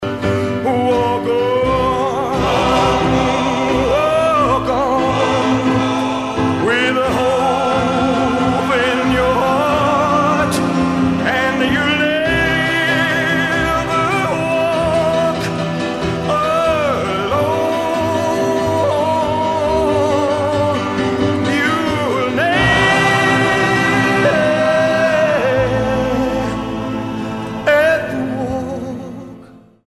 STYLE: Southern Gospel